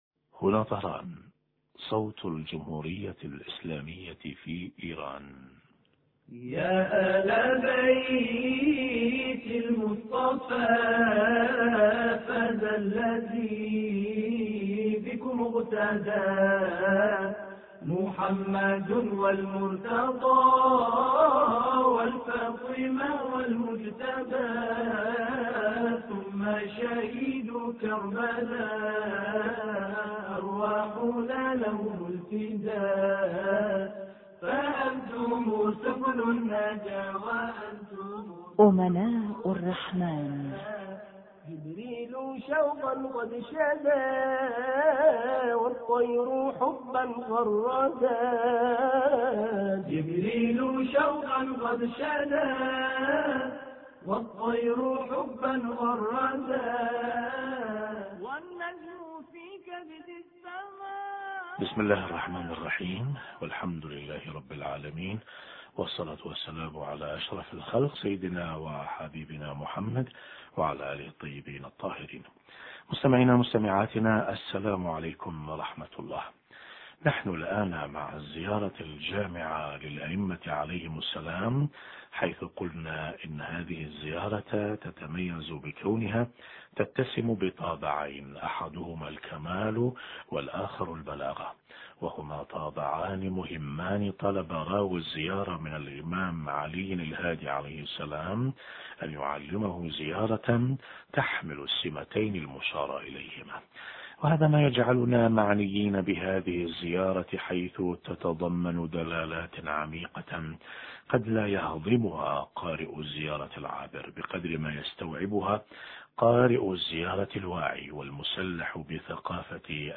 أما الآن نتابع تقديم برنامج امناء الرحمن بهذا الاتصال الهاتفي